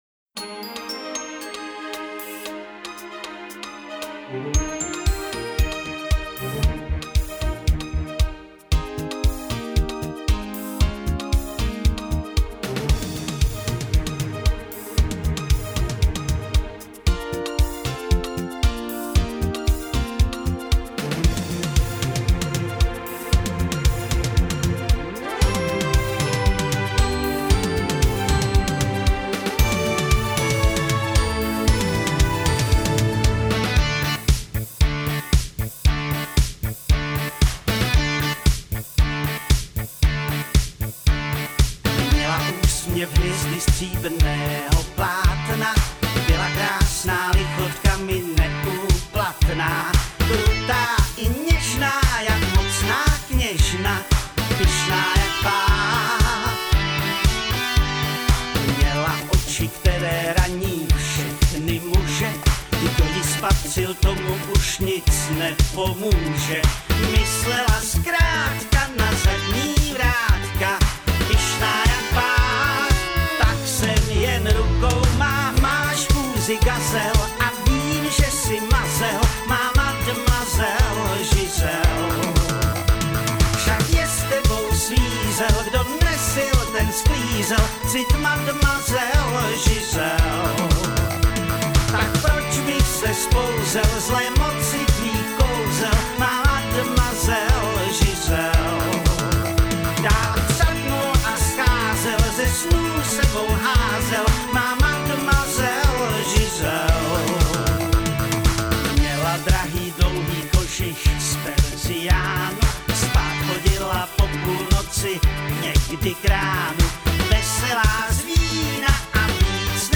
Вариант с пластинки 1973 года.
Да, точно...Меня инструментальное вступление сбило...